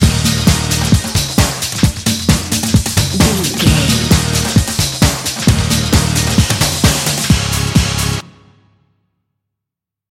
Uplifting
Ionian/Major
D
drum machine
synthesiser
bass guitar